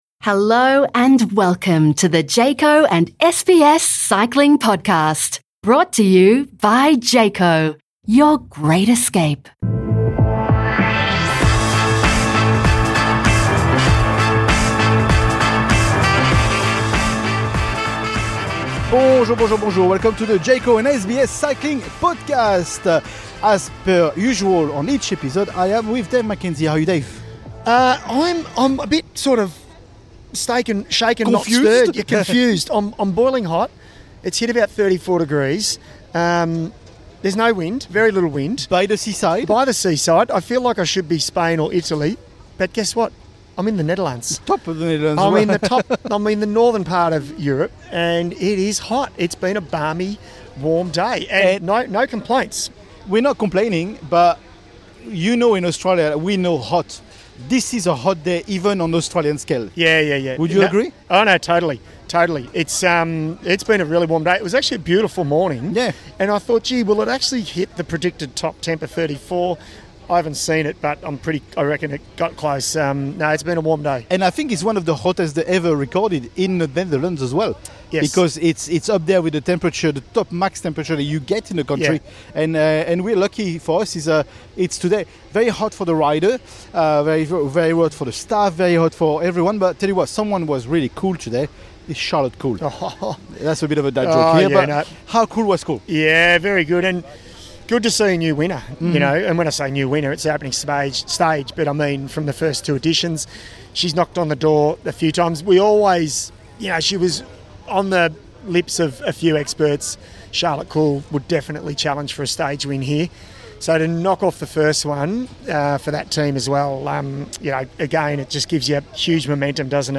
A standout interview with Olympic champion Grace Brown provides a personal look into her journey to gold and her aspirations for the Tour. The episode concludes with a strategic preview of the upcoming double stage day, promising a mix of sprinting and time-trial challenges that could shake up the overall standings.